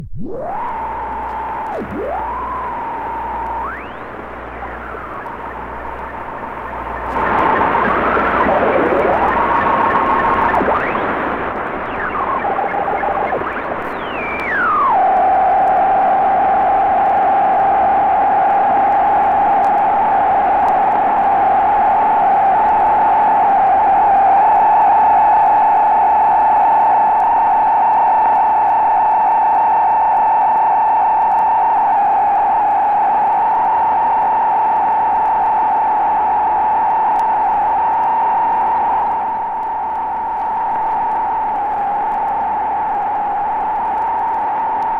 58年に打ち上げられた米ソの13機からの音。宇宙へ飛び立った犬、ライカの心音も。多くのフィールドレコーディング同様に、刺激溢れる音の世界です。
Field Recording, Non Music　USA　12inchレコード　33rpm　Mono